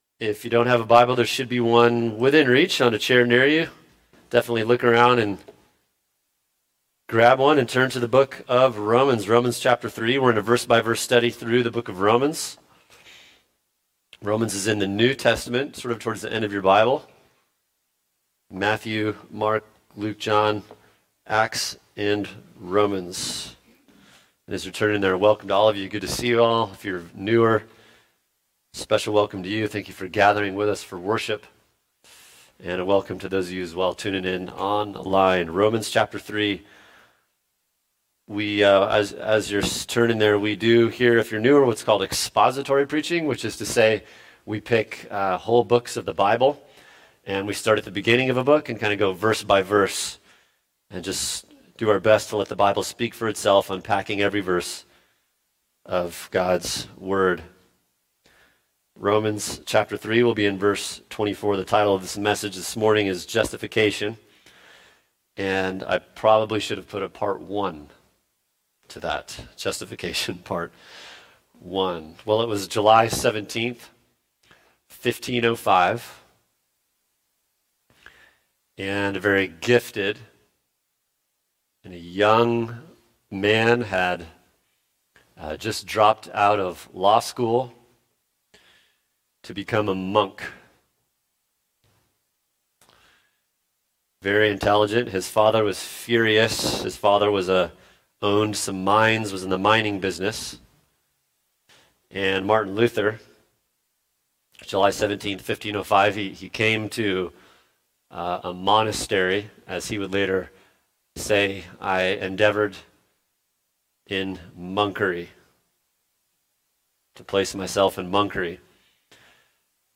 [sermon] Romans 3:24 Justification: The Need, Definition, & Imputation | Cornerstone Church - Jackson Hole